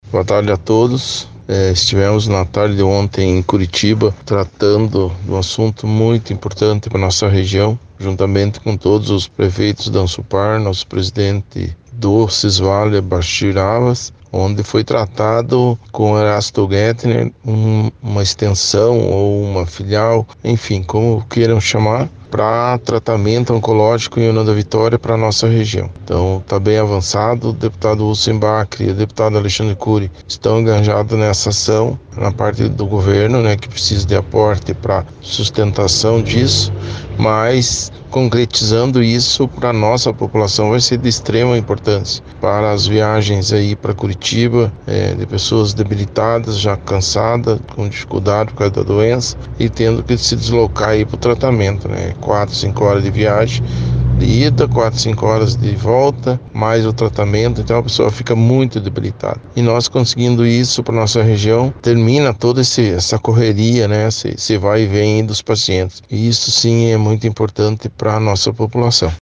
Acompanhe a fala dos prefeitos de União da Vitória, Bachir Abbas; de General Carneiro, Joel Ferreira; de Cruz Machado, Antonio Szaykowski; e também da prefeita de Porto Vitória, Marisa Ilkiu, sobre a visita.
Prefeito Joel Ferreira: